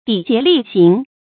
砥节砺行 dǐ jié lì xíng
砥节砺行发音